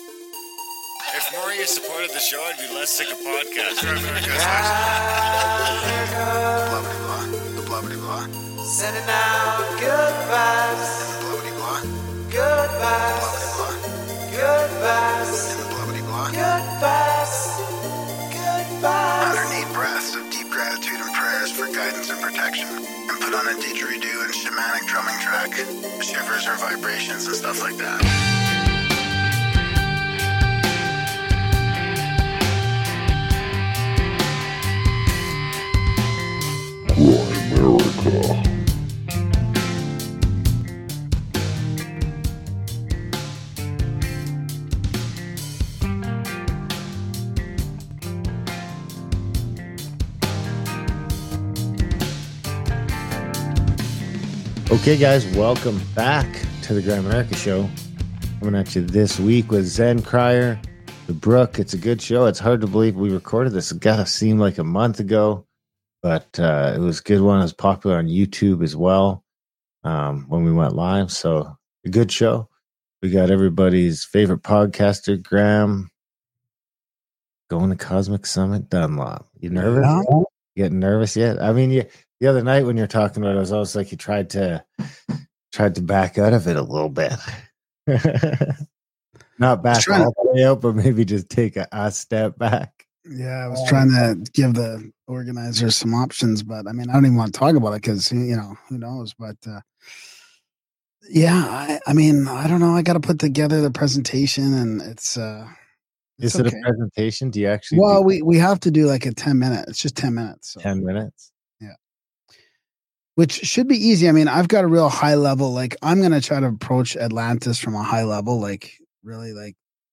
Interview starts at 30:25